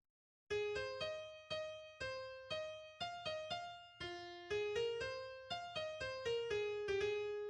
This melody is followed by "Pretty Caroline"[6] (Roud 1448) as a quiet air for solo clarinet or solo cornet (clarinet only in orchestrated version), which is also repeated.